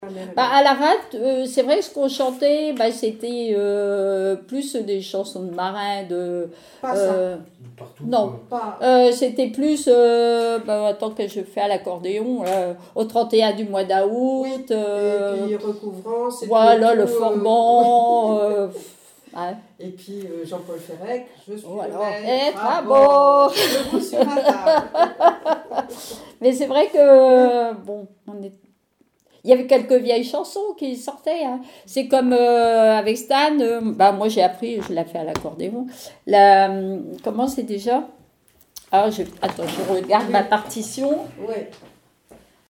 chanteur(s), chant, chanson, chansonnette
témoignages et bribes de chansons
Catégorie Témoignage